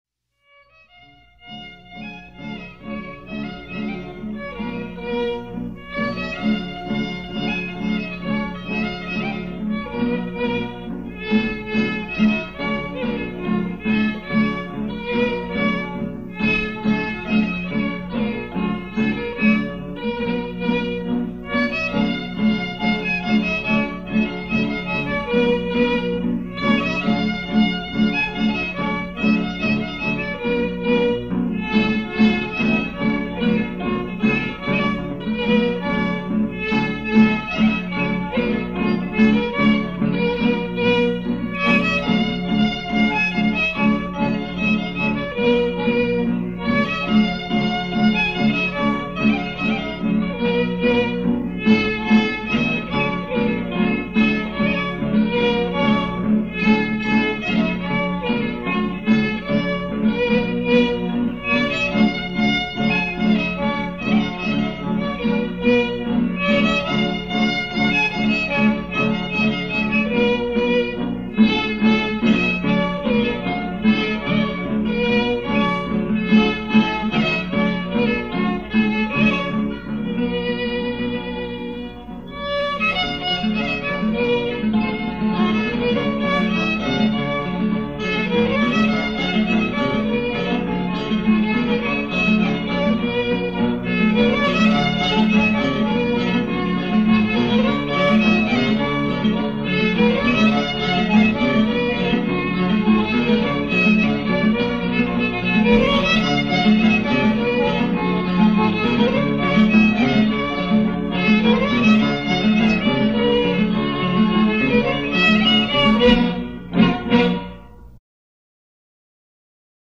Producción sonora que presenta una selección de registros de expresiones musicales tradicionales vinculadas a la celebración de la Navidad en diversas regiones del Perú.
Cancionero, Canciones navideñas, Villancicos en quechua, Villancicos en español